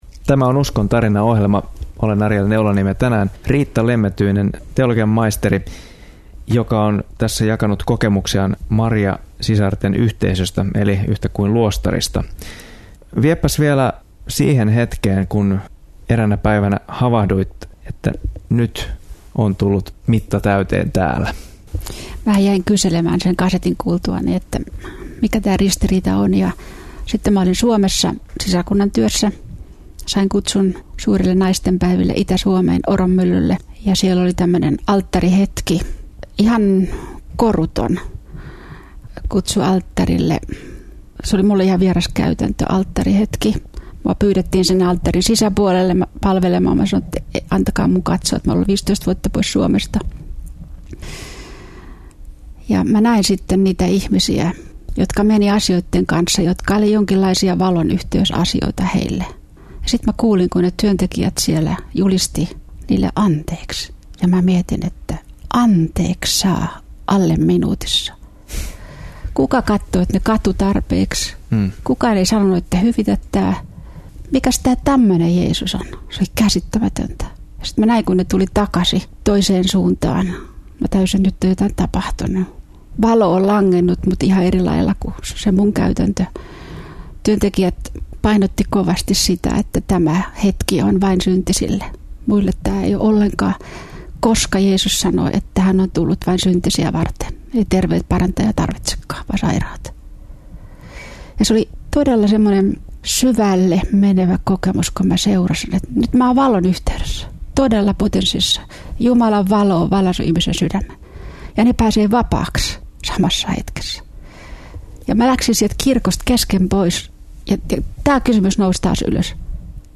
Haastattelun osa 3: